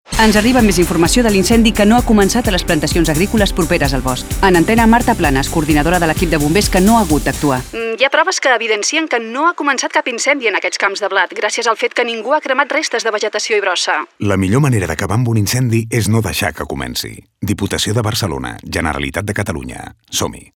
Així, els espots de ràdio i televisió tenen un format proper al documental, que permeten seguir el testimoni de diverses persones, com pagesos, ciutadans particulars o membres dels equips d’emergència, entre d’altres.
Falca ràdio. Bombers i cigarreta